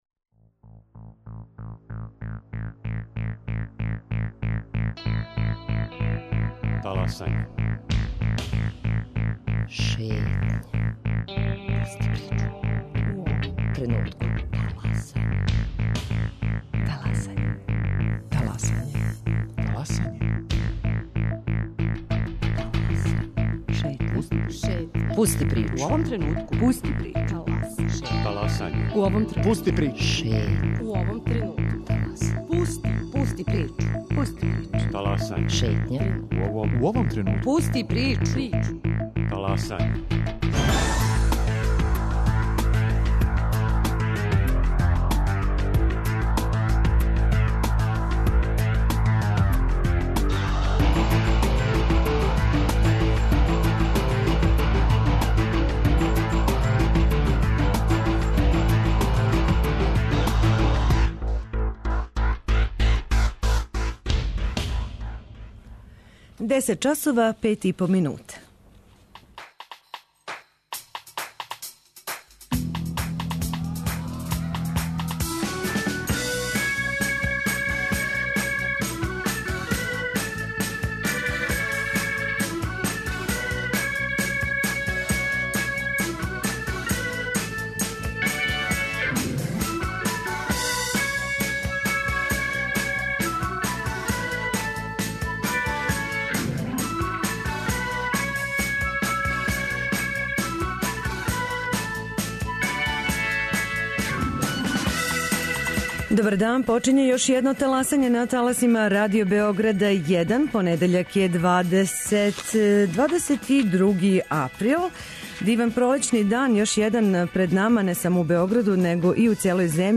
О томе где је Руанда данас, деветнаест година касније, какав је њен утицај у региону, зашто највећа држава подсахарске Африке, ДР Конго, зазире од те, десет пута мање, државе - чућете од новинара из Србије који су деведесетих година били на афричком континенту.